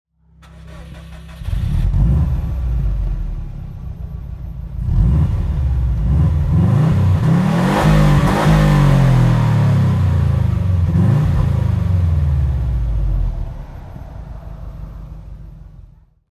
Subaru Impreza WRX STi (2001) - Starten und Leerlauf
Subaru_Impreza_WRX_STi_2001.mp3